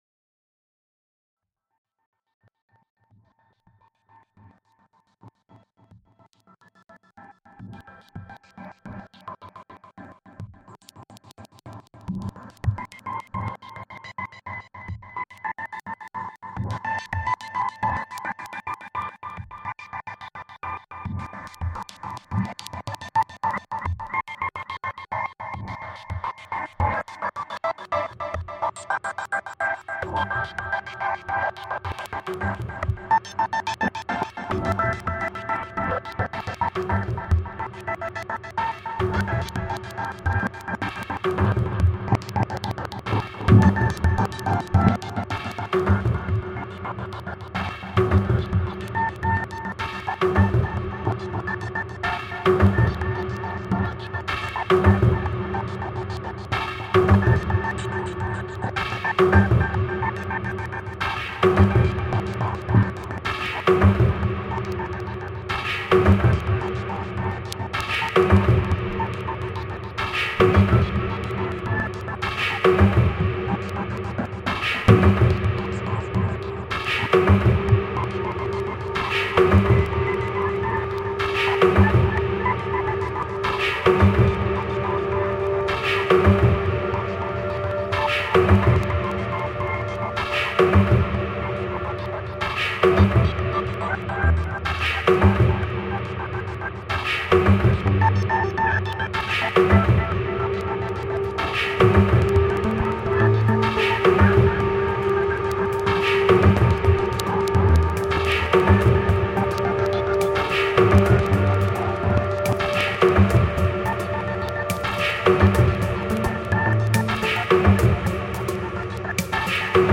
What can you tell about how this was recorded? Mexico street band in lockdown reimagined